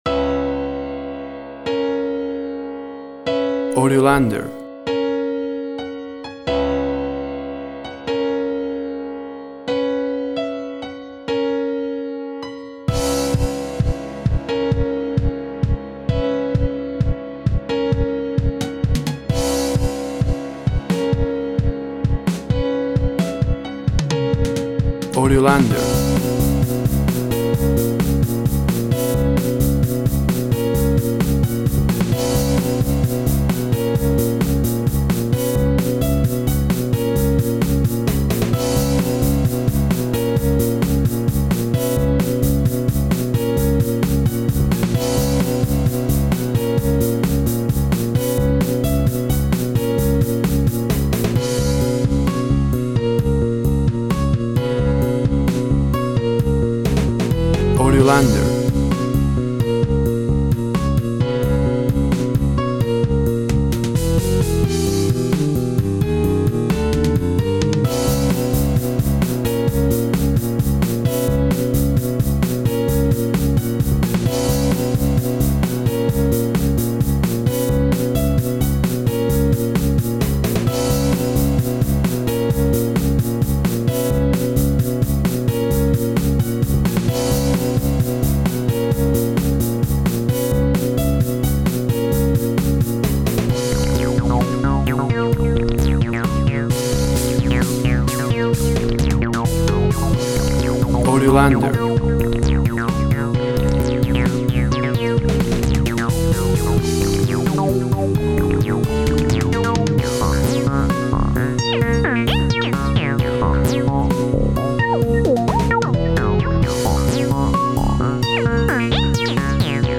Electronic Track, 7/8, and Urban sounds.
Tempo (BPM) 111